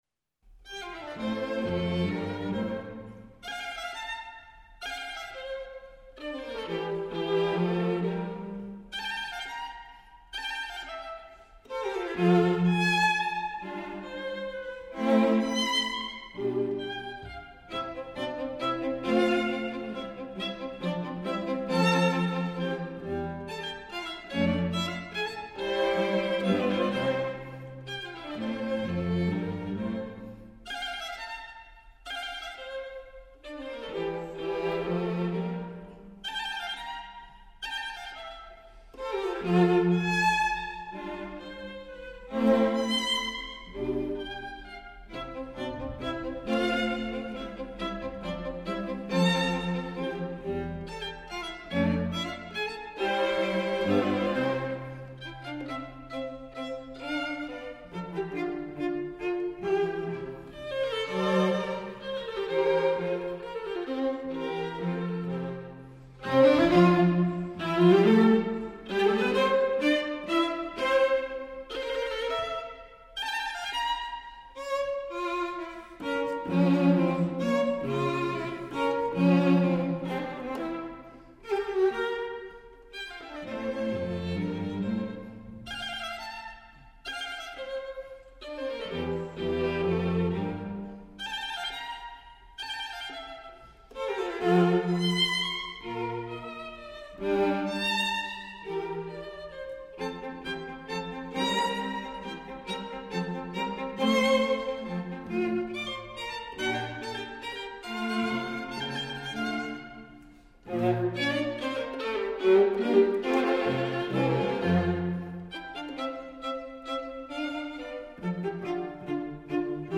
Tempo di Menuetto